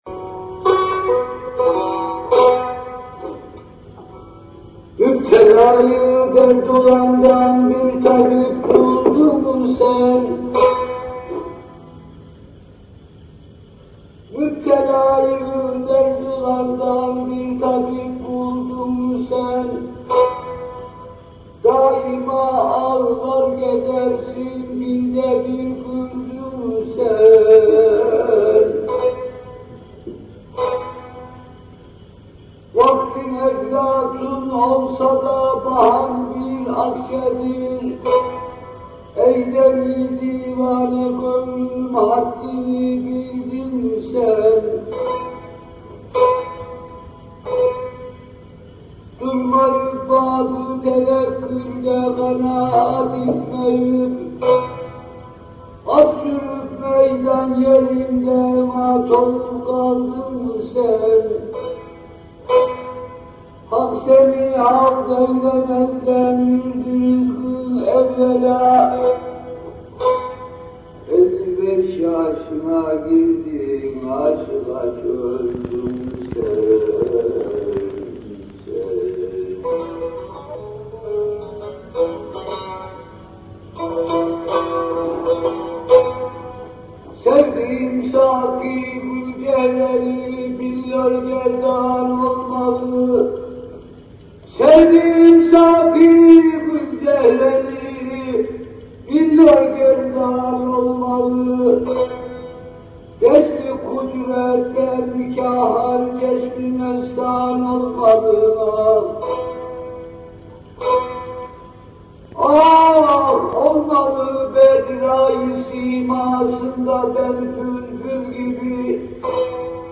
Etiketler: ankara, türkü, müzik, türkiye